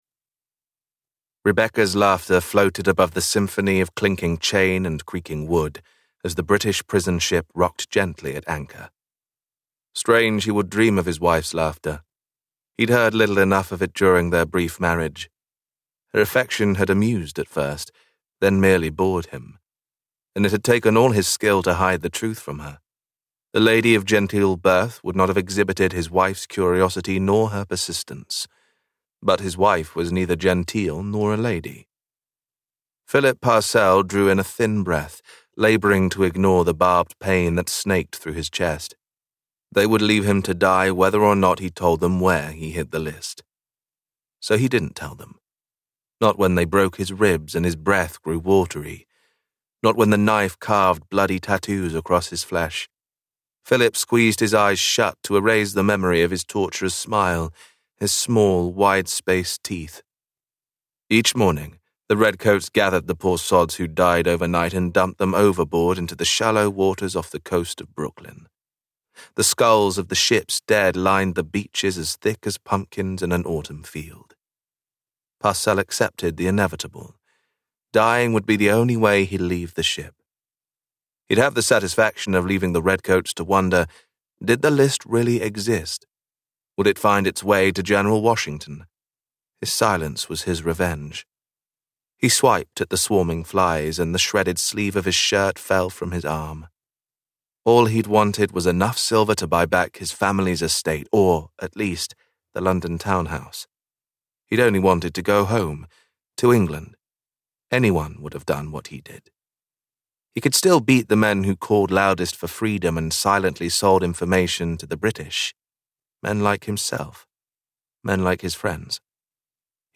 The Turncoat's Widow - A Revolutionary War Mystery, Book One - Vibrance Press Audiobooks - Vibrance Press Audiobooks